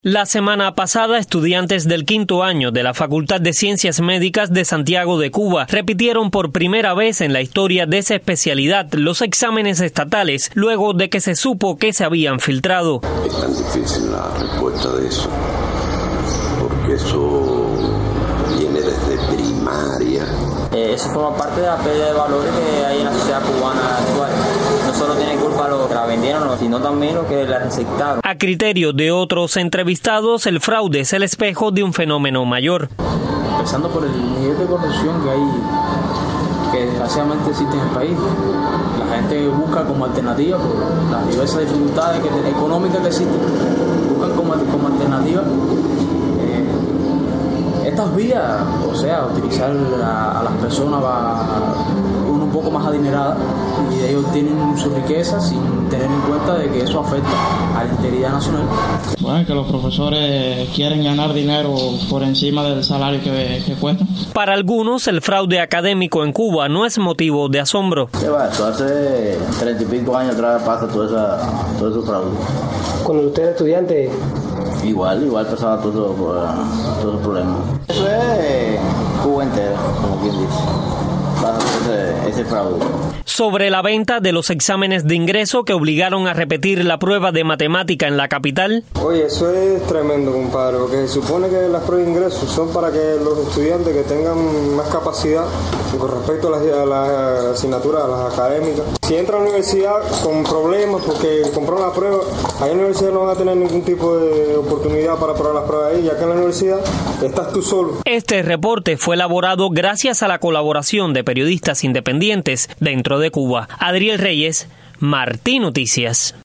presenta varias opiniones recogidas por periodistas independientes.